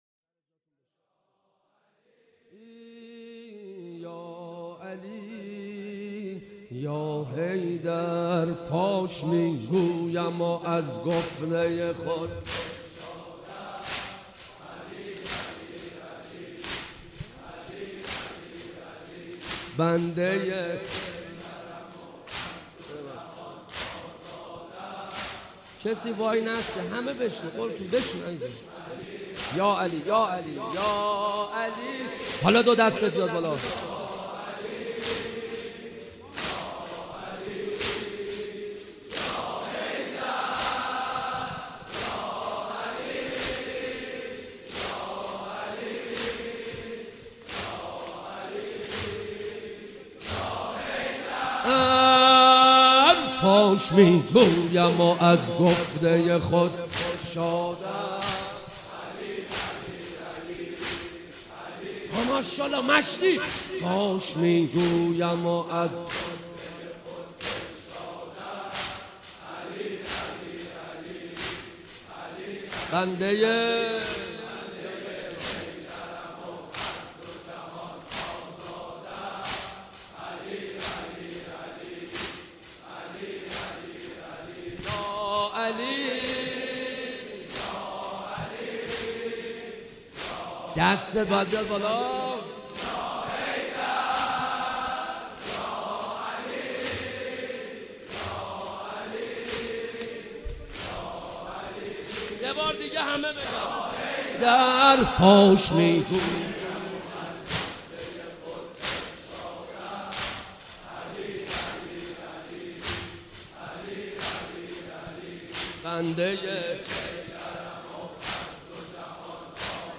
شب سوم محرم - به نام نامیِ حضرت رقیه(س)